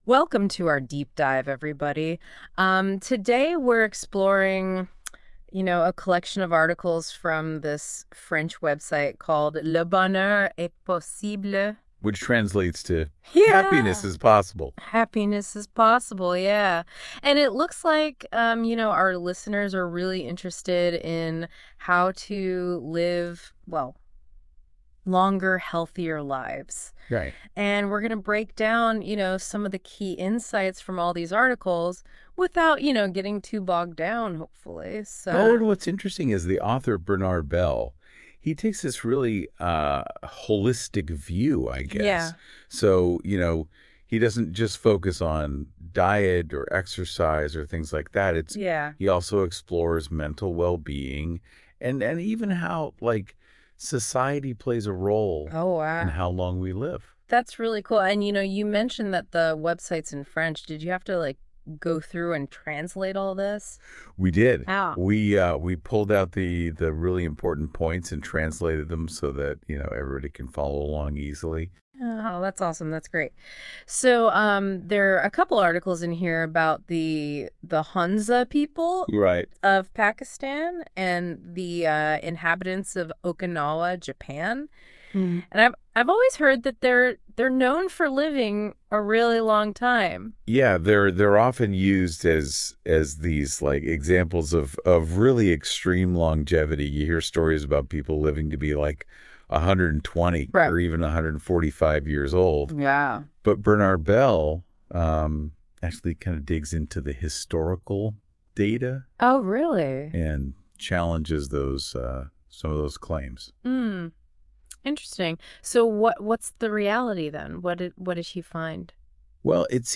👉 Listen to a presentation in American English created by NotebookLM